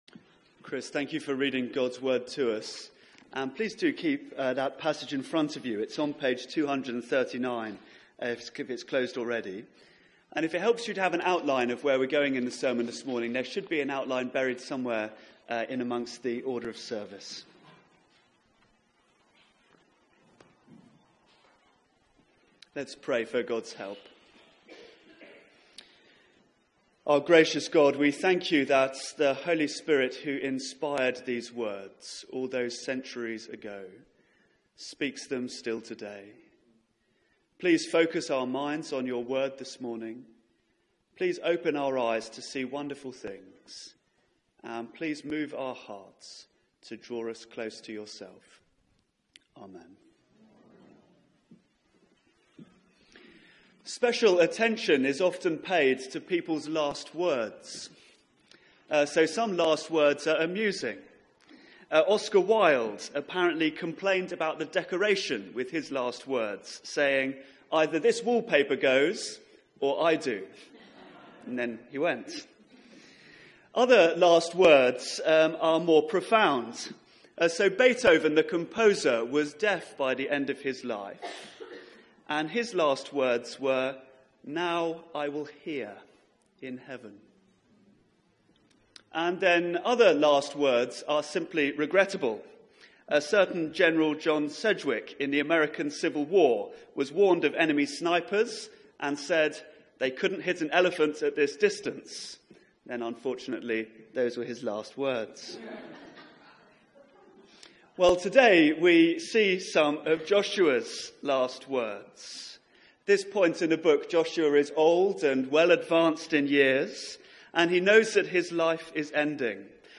Media for 9:15am Service on Sun 13th Aug 2017
Theme: Staying Faithful to God Sermon